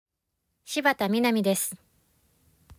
ボイスサンプルはこちら↓ 名前